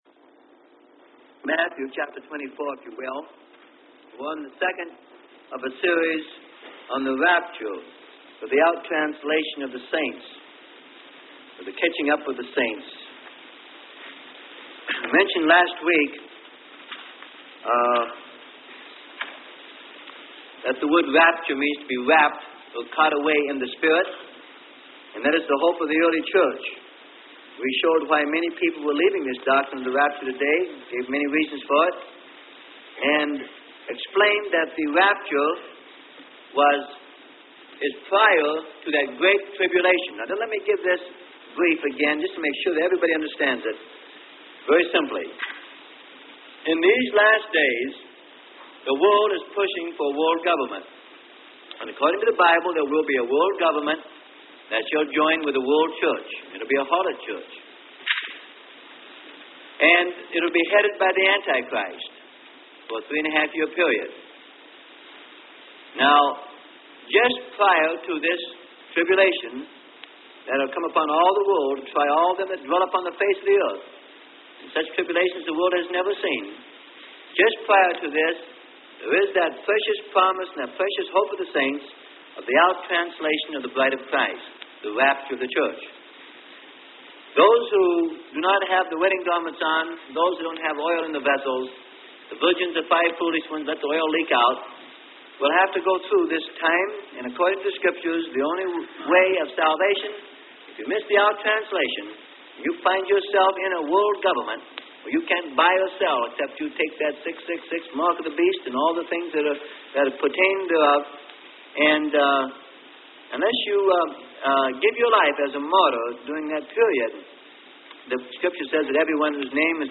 Sermon: Rapture - Part 2 - Freely Given Online Library